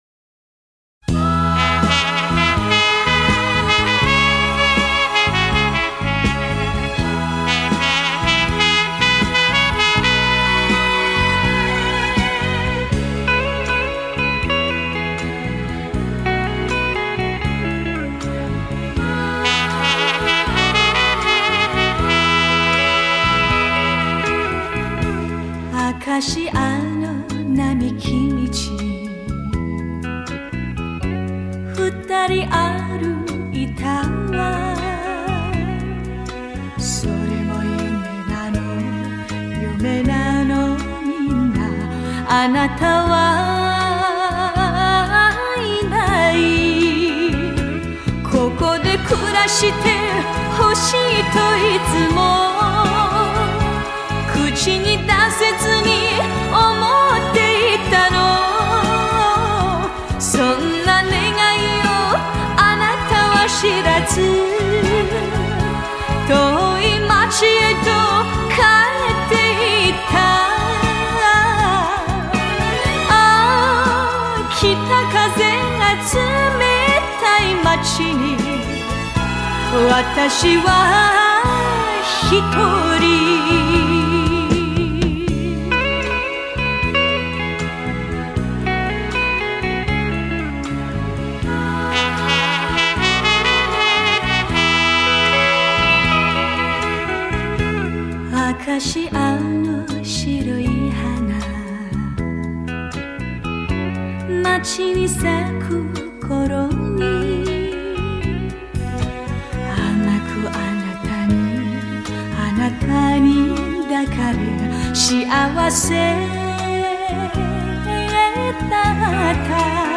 デジタルリマスター音源。